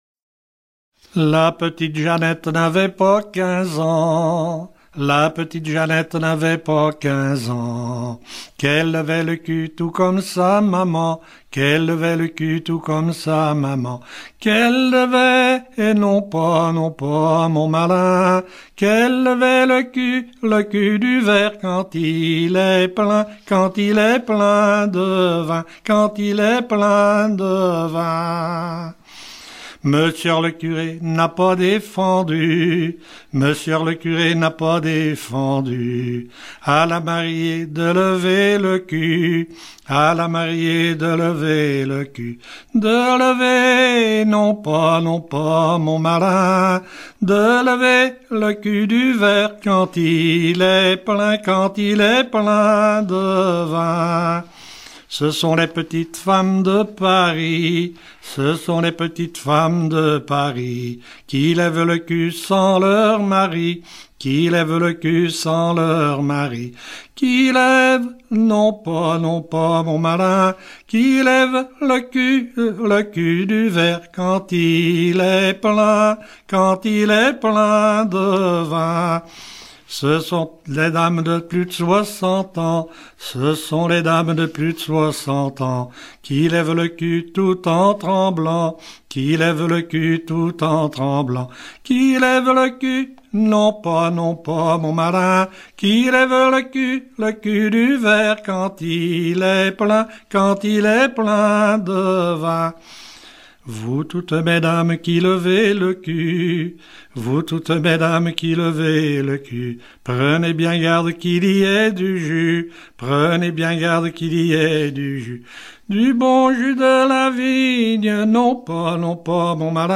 circonstance : bachique
Genre énumérative